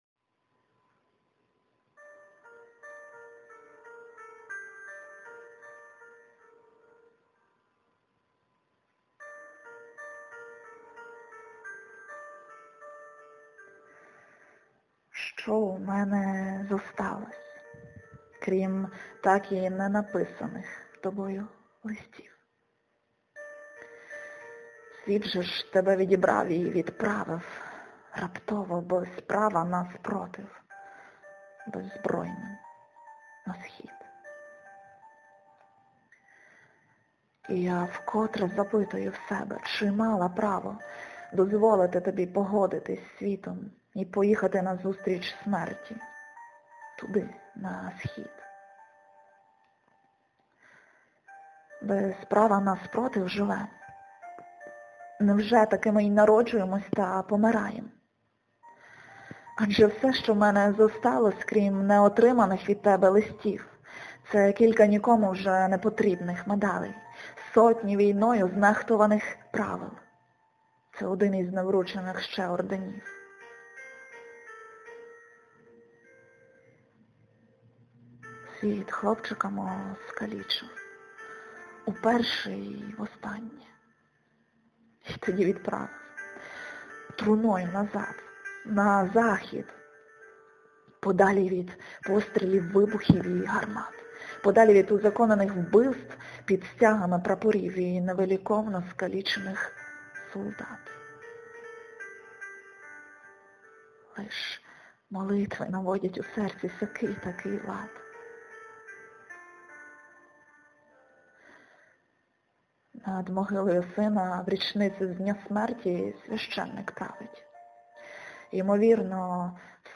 (фон: Fabrizio Paterlini - Found Letters)
люблю твої речитативи give_rose